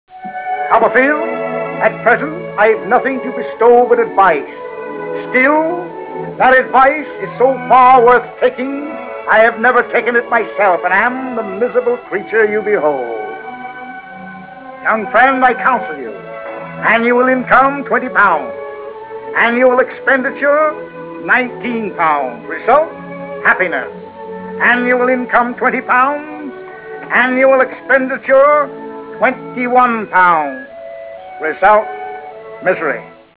Frase celebre